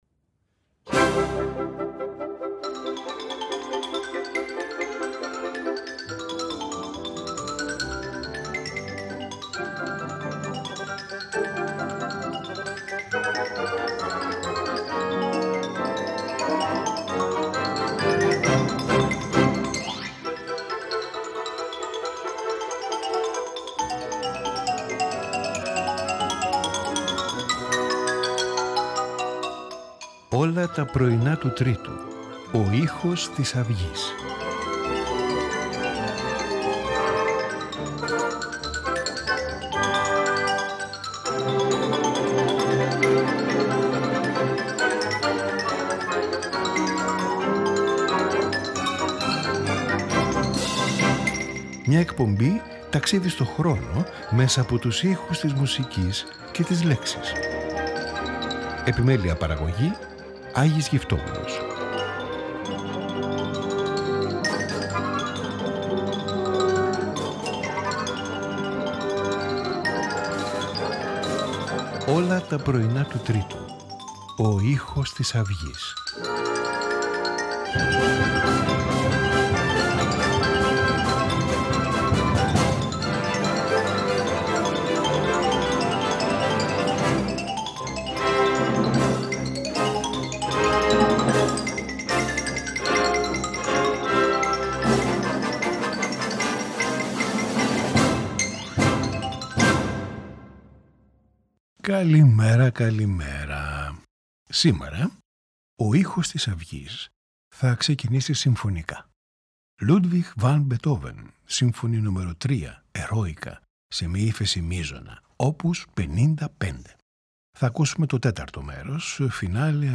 Hungarian rhapsody no 6 in D